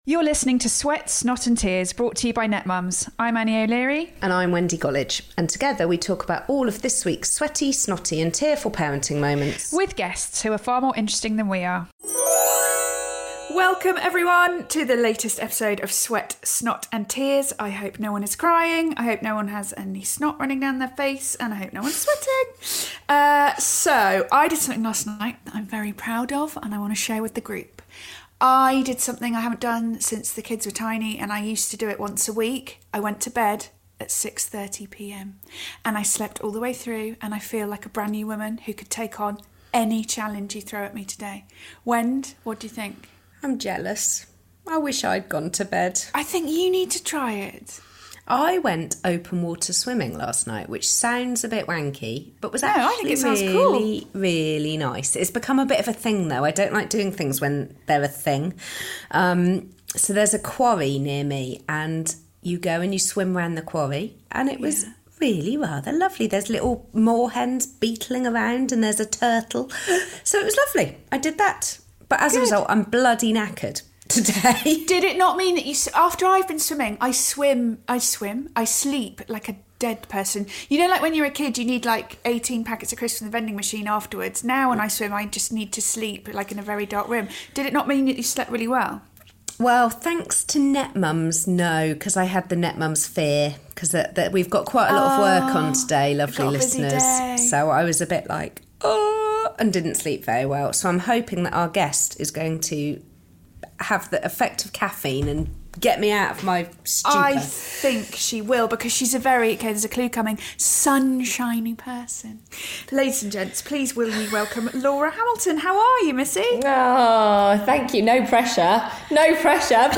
Listen as the ‘A Place In The Sun’ presenter shares how she realised she was suffering from panic attacks during lockdown, and how she's learnt to manage them, PLUS how to juggle EVERYTHING as a working mum of two.